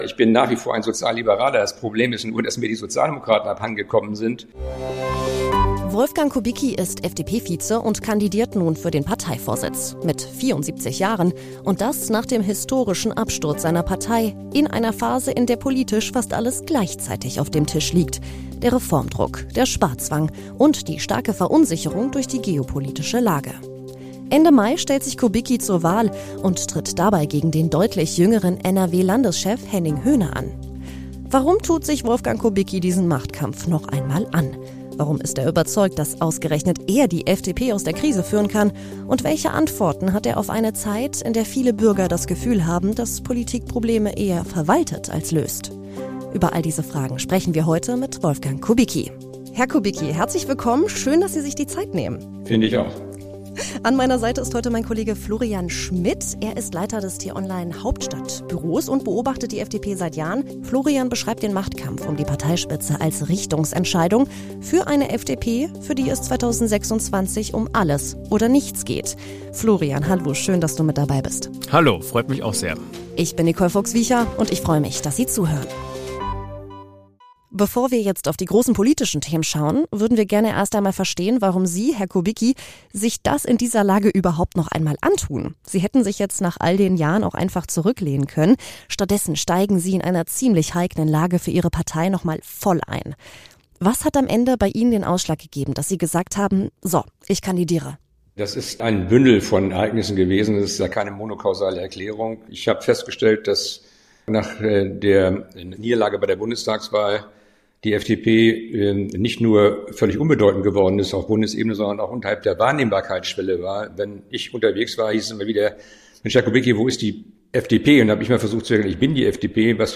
Wolfgang Kubicki will FDP-Chef werden. In dieser Folge sprechen wir mit ihm über seinen Machtkampf mit Henning Höne, über den Absturz der FDP und über die Frage, warum er trotzdem glaubt, die Partei noch einmal nach vorn bringen zu können.